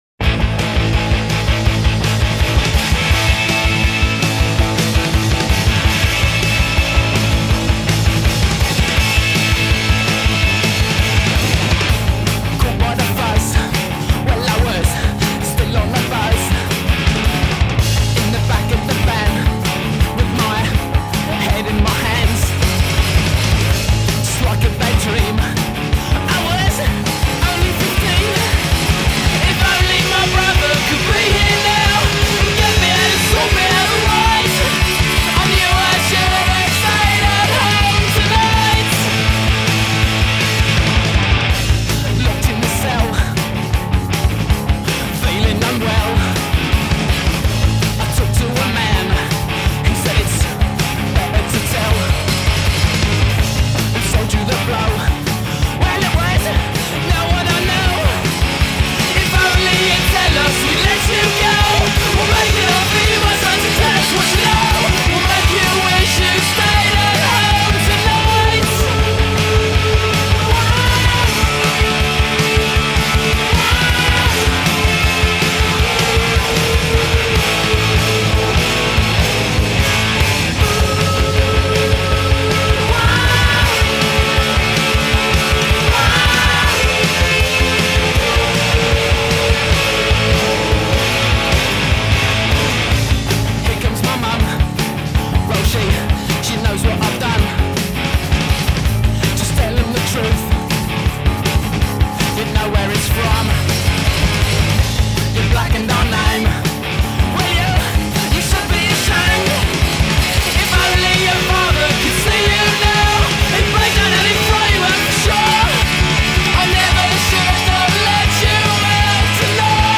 The single/album